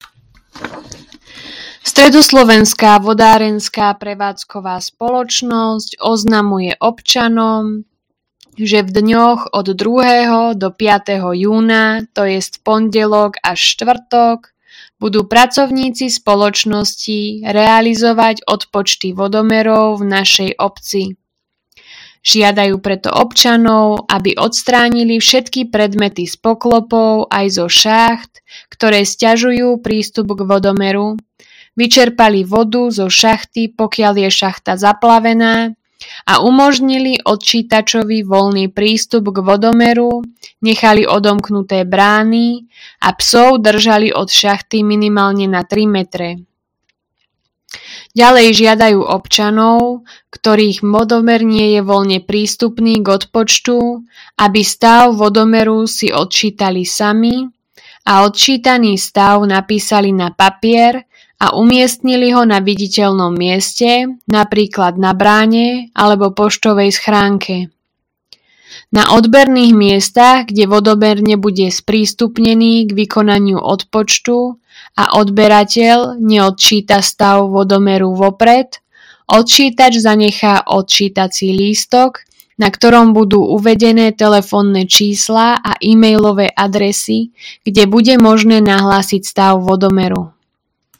Hlásenie obecného rozhlasu – Odpočet vodomerov